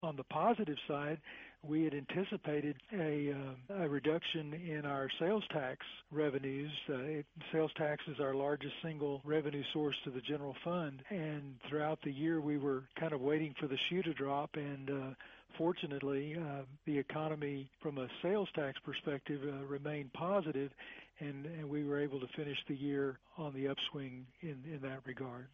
City Manager Terry Roberts tells a little bit about the events that made 2015 an exciting and dynamic year, the first being a look at the property tax rate for the city.